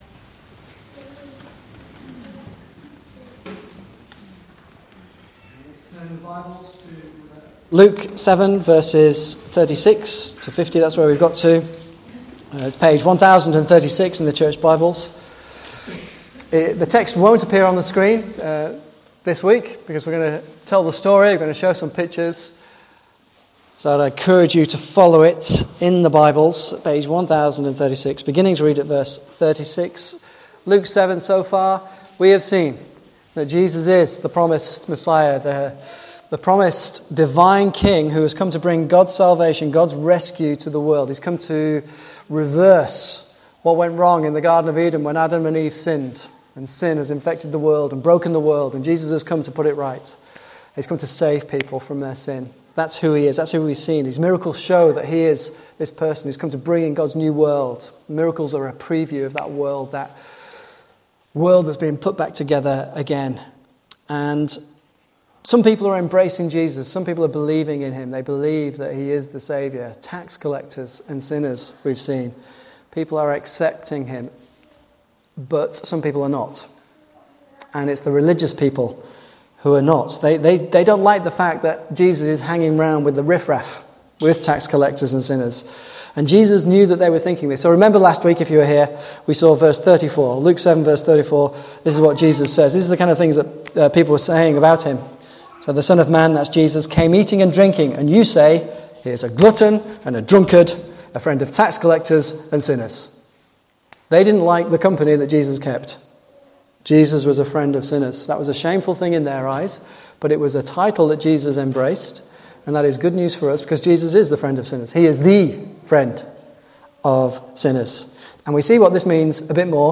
Service Type: Morning Service 11:15